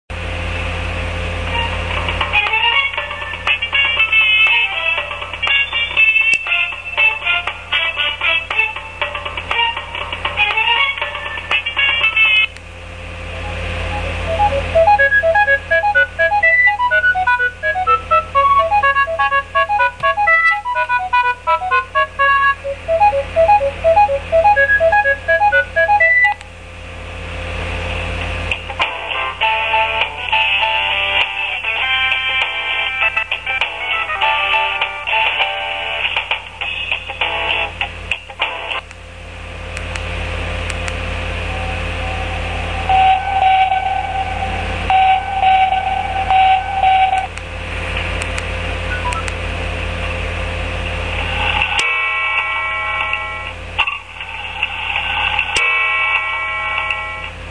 40 tone polyphonic melodies are supported.
I cant say that the sound level is too low but it should be a bit louder.